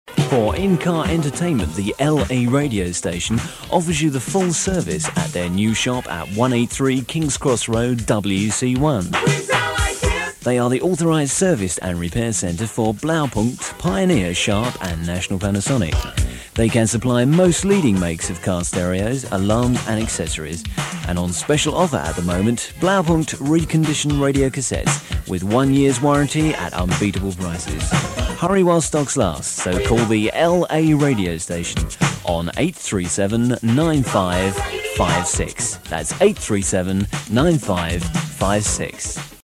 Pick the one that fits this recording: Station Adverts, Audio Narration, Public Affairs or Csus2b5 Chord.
Station Adverts